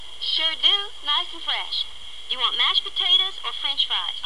下列紅色部份為省音，已省去不唸；而字串連結為連音。